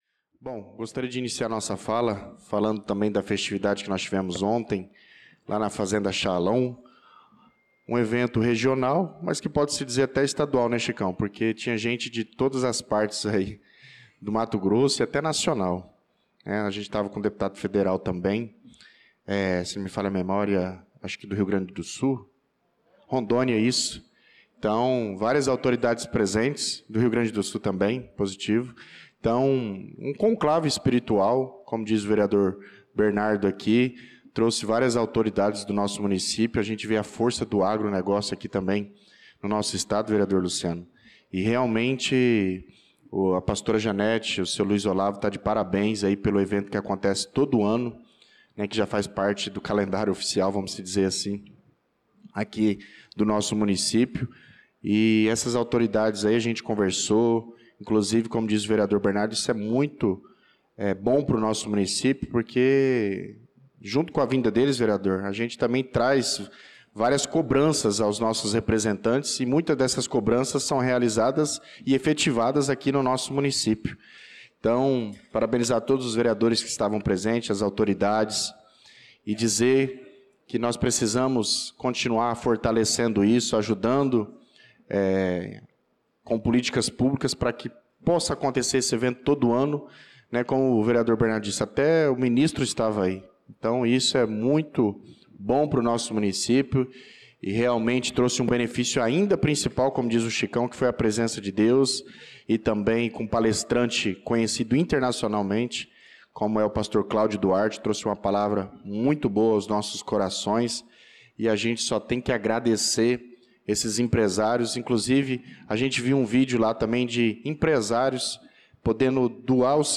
Pronunciamento do vereador Douglas Teixeira na Sessão Ordinária do dia 18/08/2025.